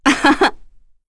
Epis-Vox-Laugh2.wav